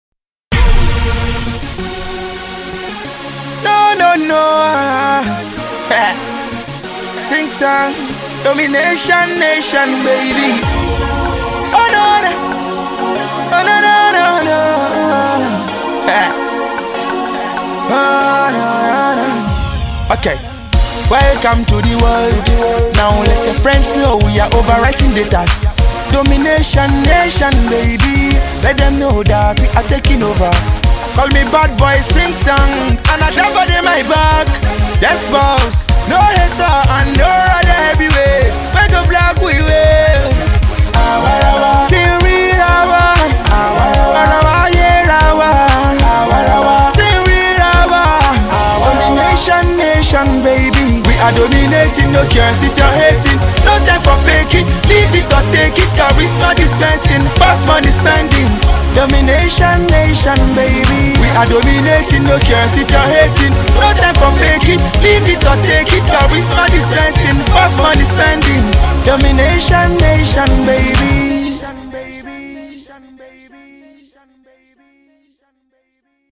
here is a freestyle as a devotion to his Label.